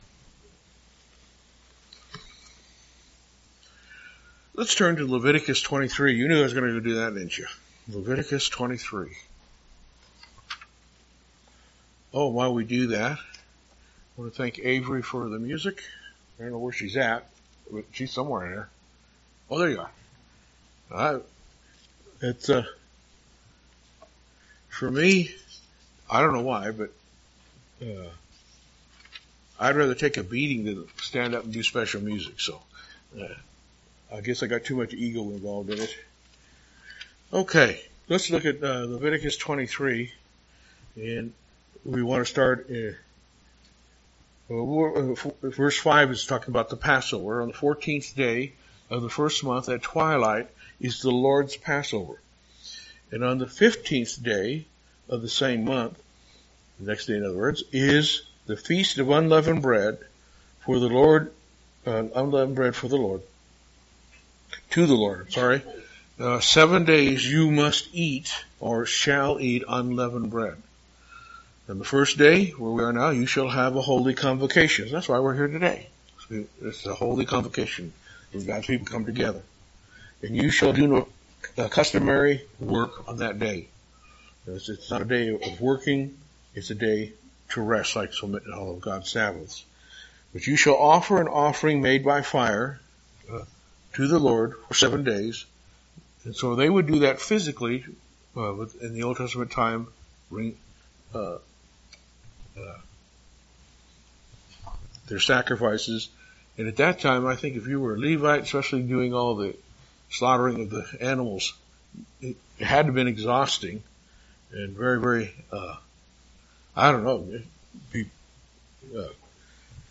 Holy Day Services Holy Days Studying the bible?